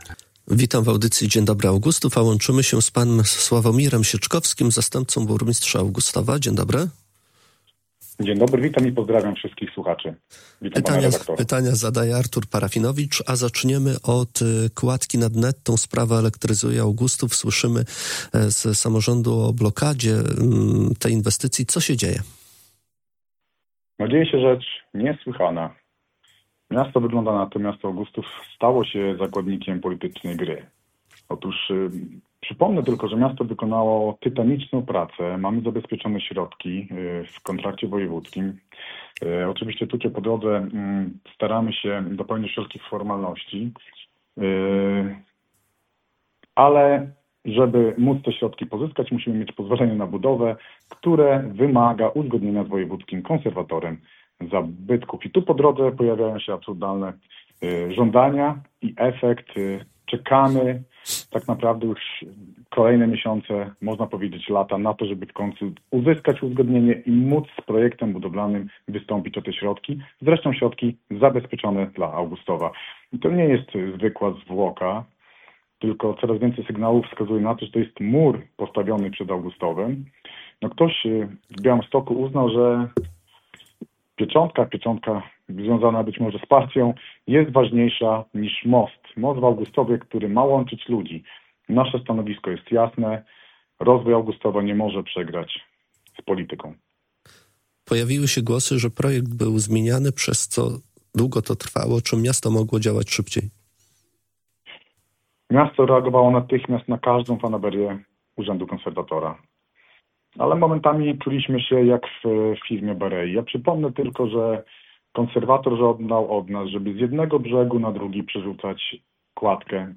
Więcej na ten temat w audycji Dzień dobry Augustów mówił Sławomir Sieczkowski, zastępca burmistrza Augustowa.